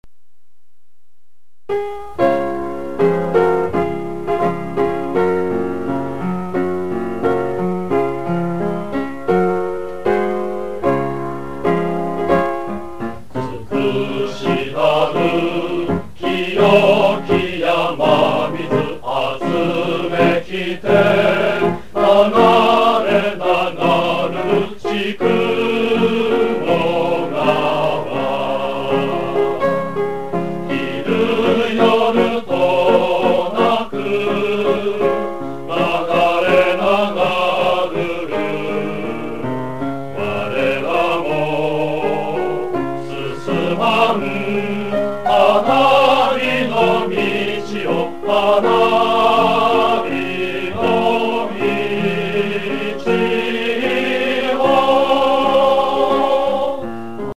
メロディーを聞いて思い出してください。
応援歌（暁雲明けて）は語り継がれてきたものであり、年代によってメロディーが少しずつ違いますが、